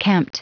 Prononciation du mot kempt en anglais (fichier audio)
Prononciation du mot : kempt